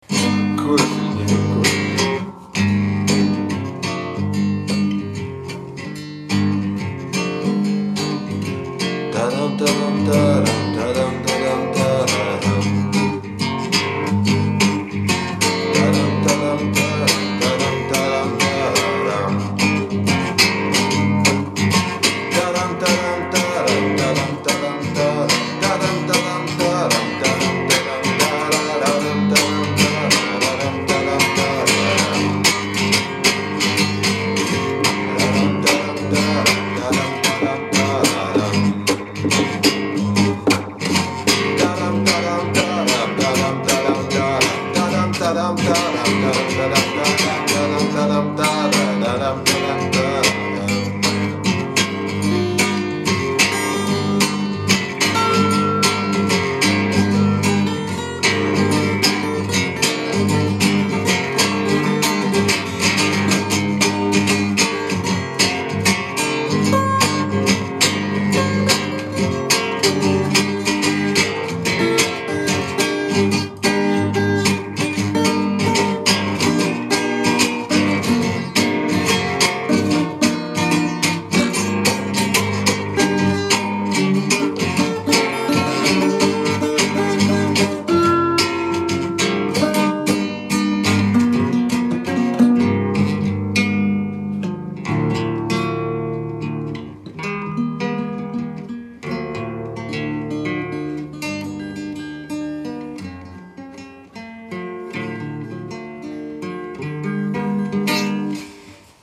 гитара    Обложка